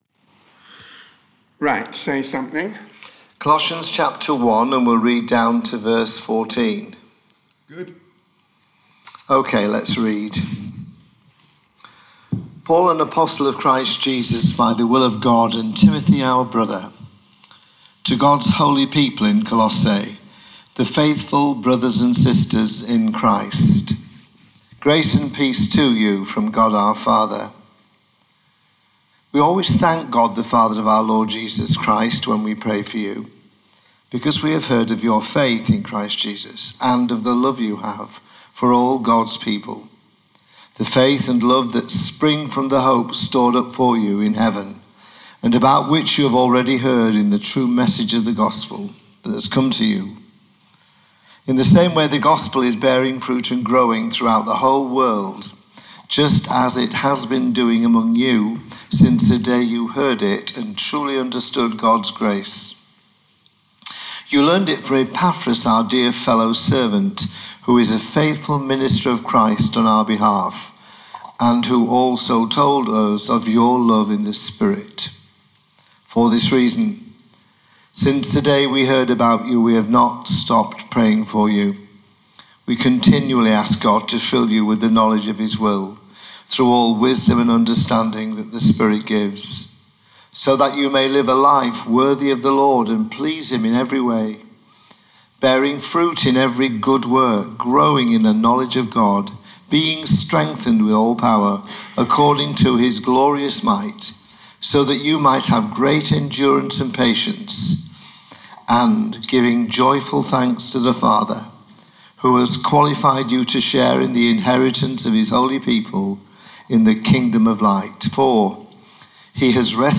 This week the online service is a �replay� of the in-person service on November 30 th .
The Message: � An Introduction to The Epistle This message is the first in our series.